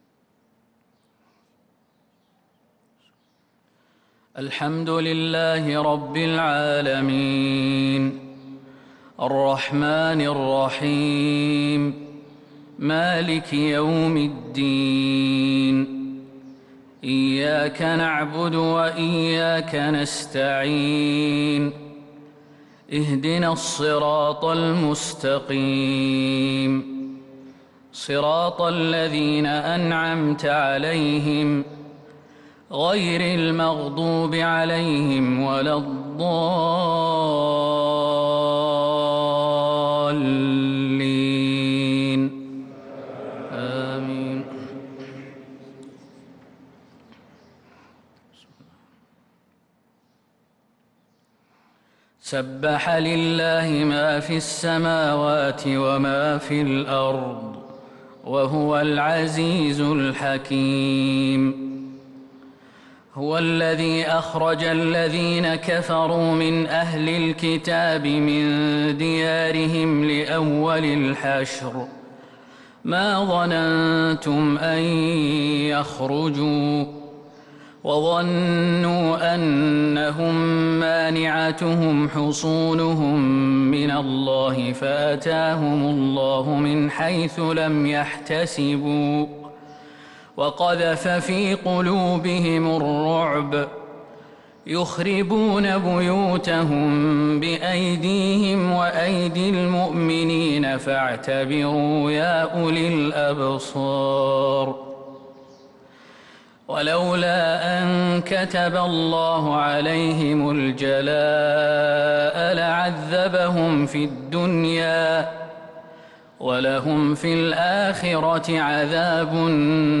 صلاة الفجر للقارئ خالد المهنا 4 رمضان 1443 هـ